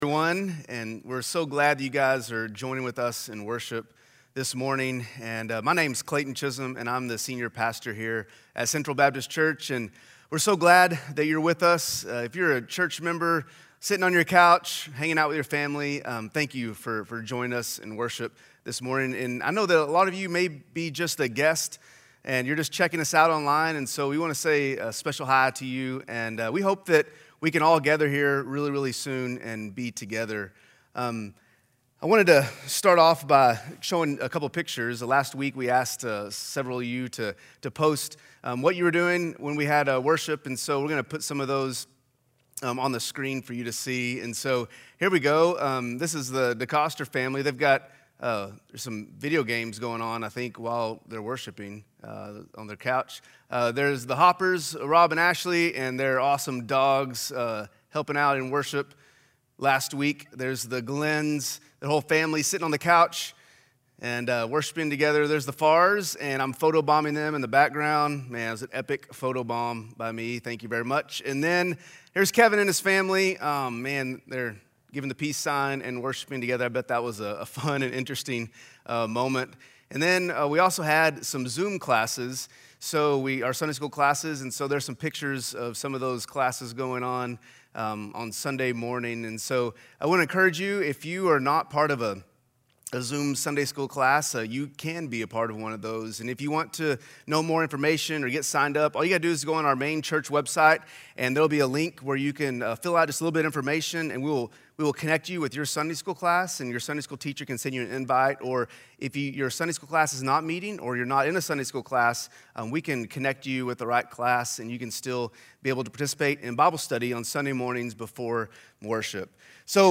A message from the series "Asking for a Friend."
Palm Sunday service from Central Baptist Church Owasso on Vimeo.